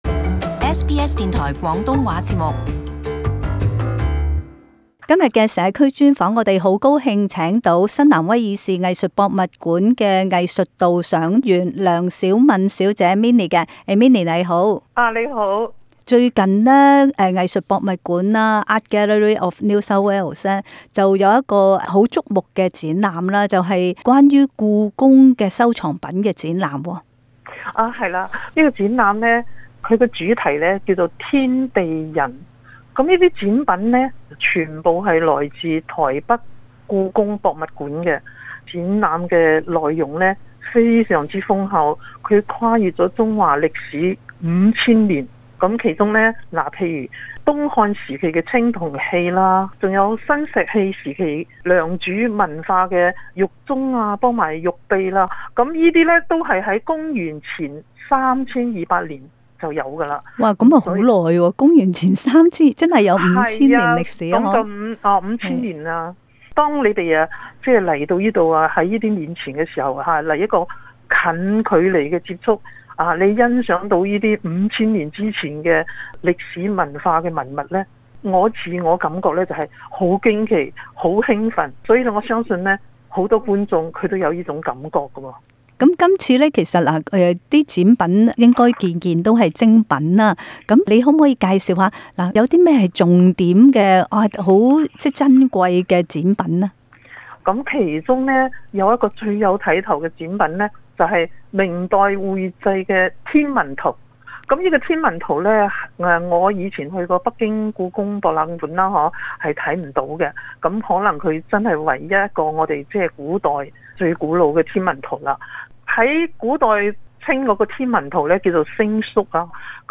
【社区专访】故宫博物院珍宝展